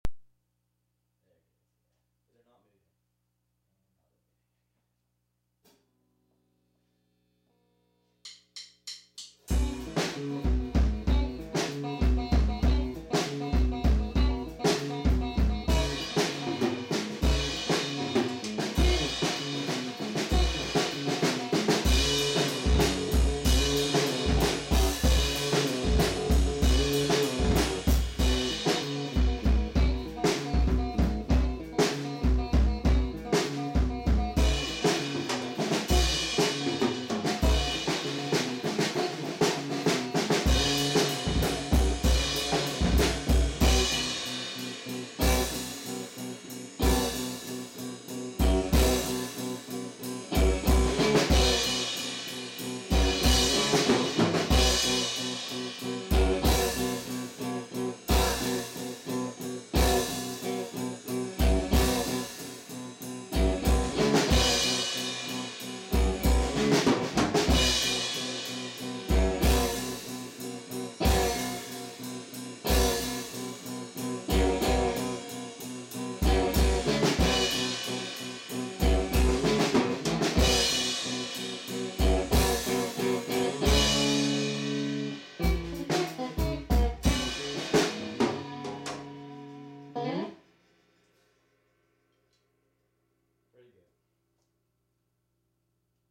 (practice2 take2)